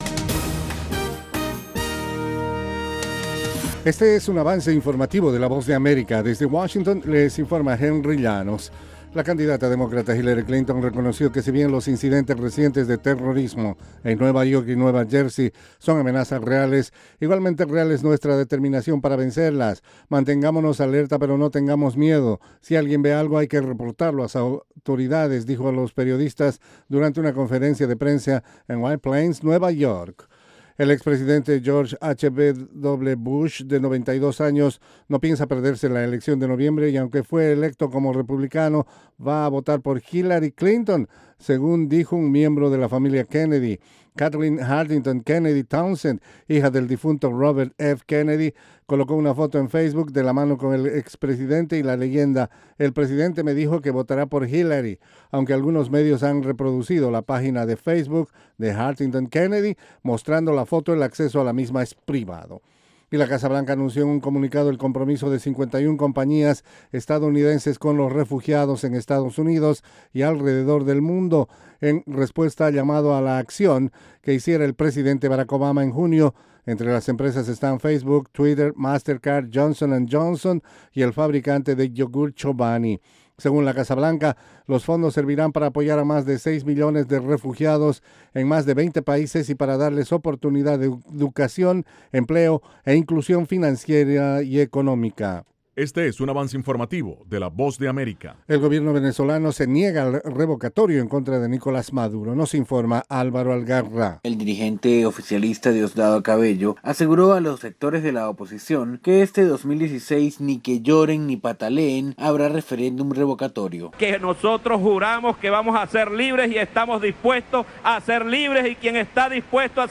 Avance Informativo - 10:00am